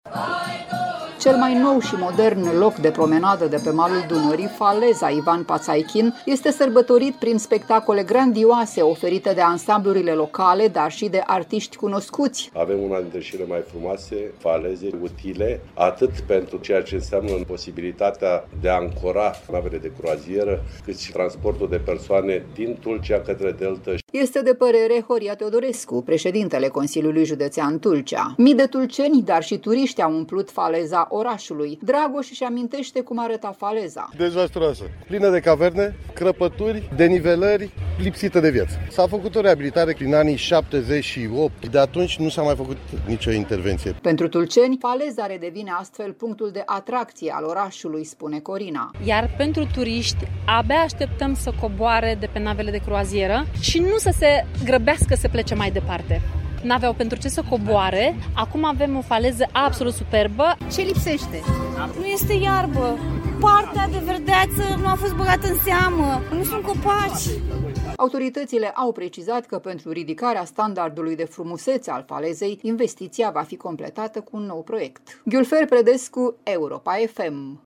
Cel mai nou și modern loc de promenadă de la malul Dunării, Faleza „Ivan Patzaichin” Tulcea, este sărbătorit de autoritățile județene prin spectacole grandioase oferite de ansamblurile locale dar și de artiști cunoscuți: „Avem una dintre cele mai frumoase faleze, utile, atât pentru ceea ce înseamnă posibilitatea de a ancora navele de croazieră cât și transportul de persoane de la Tulcea în Deltă”,  a spus prședintele CJ Tulcea, Horia Teodorescu.